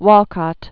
(wôlkŏt, wŏl-), Derek Alton 1930-2017.